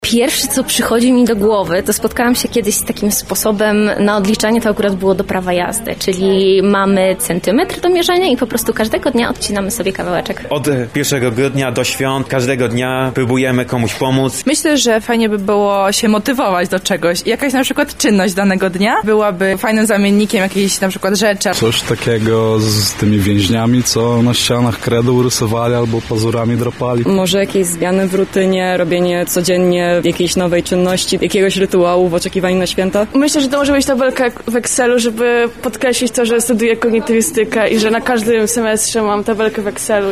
[SONDA] Jak odliczać czas do Bożego Narodzenia?
Zapytaliśmy młodych osób, jakie czynności towarzyszą im w codziennym oczekiwaniu:
Sonda kalendarze adwentowe